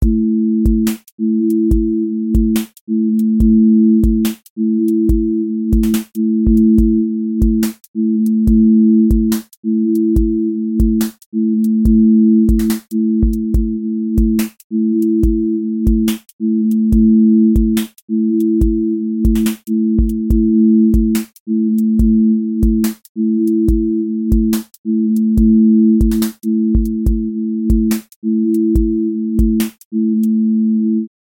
QA Listening Test drill Template: drill_glide
drill glide tension with sliding low end